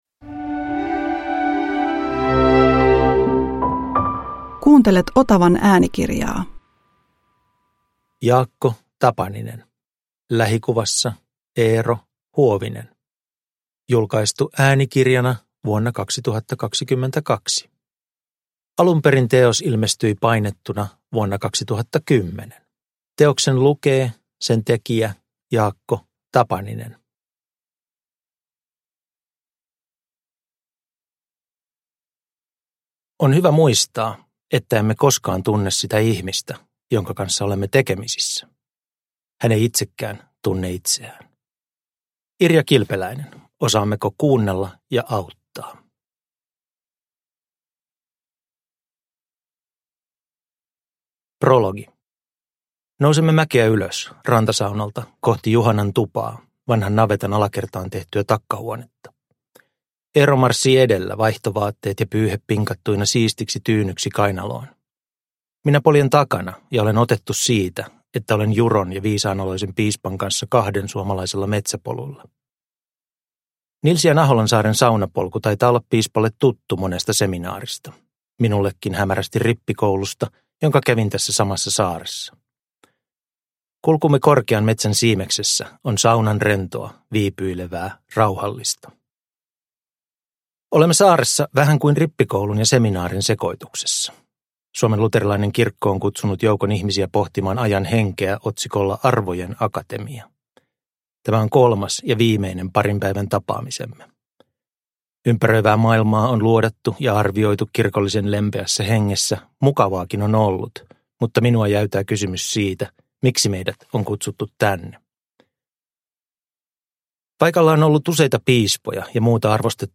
Lähikuvassa Eero Huovinen – Ljudbok – Laddas ner